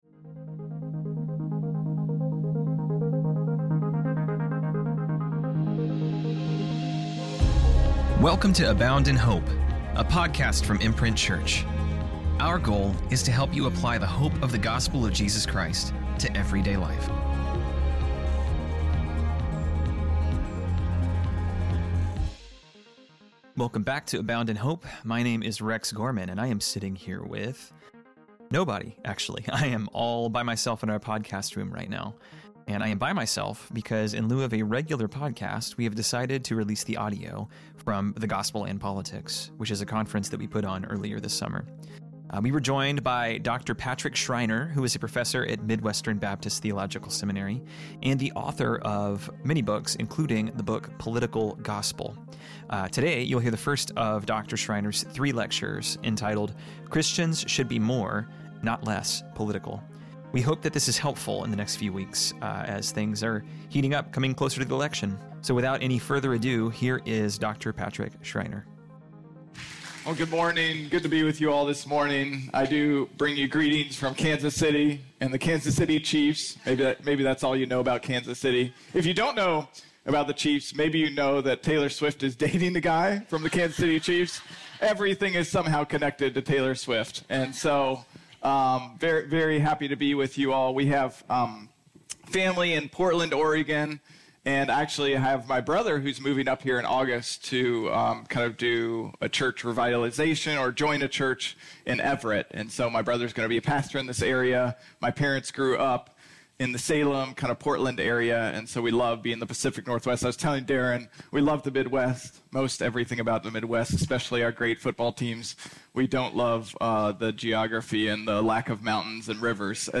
This week, we are airing the first session from The Gospel& Politics, a conference we put on in Summer of 2024.